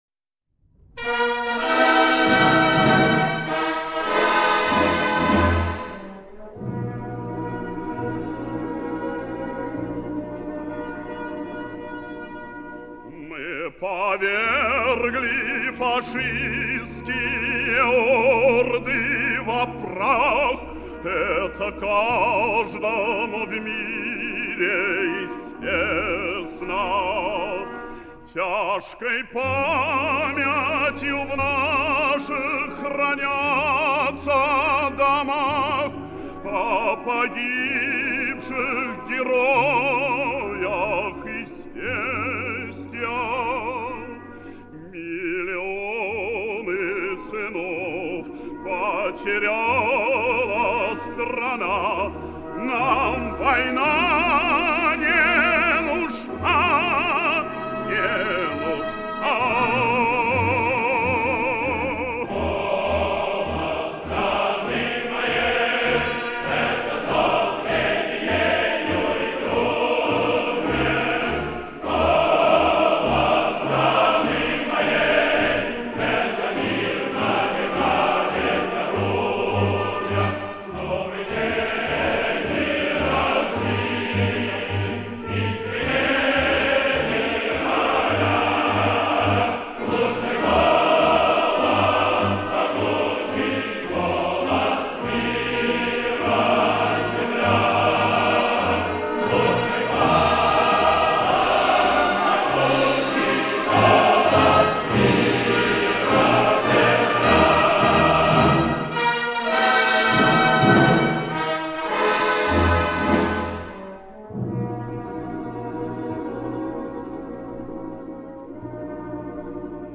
Более высокое качество версия.